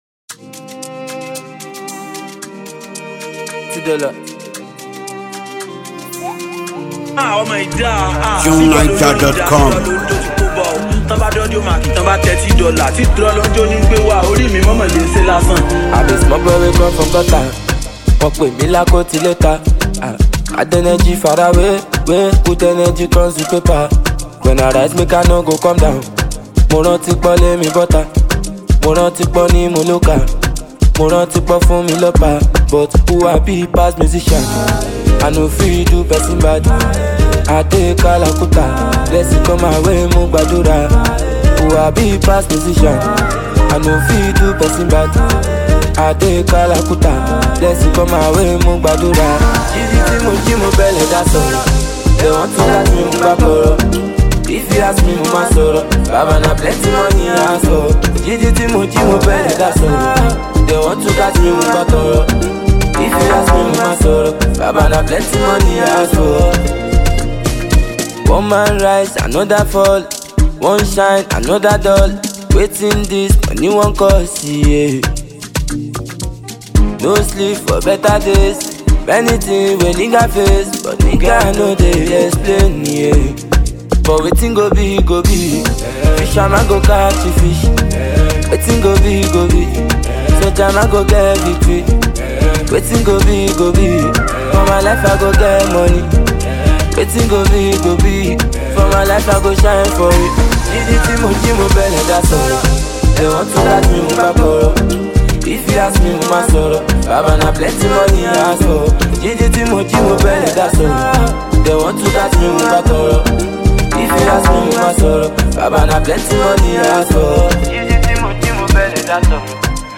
heartfelt ballad
it’s a spiritual hymn of thankfulness, optimism, and trust